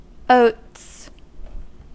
Ääntäminen
Ääntäminen GB : IPA : [əʊts] Haettu sana löytyi näillä lähdekielillä: englanti Käännös Substantiivit 1. kaura 2. kauransiemen Oats on sanan oat monikko.